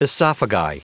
Prononciation du mot esophagi en anglais (fichier audio)
Prononciation du mot : esophagi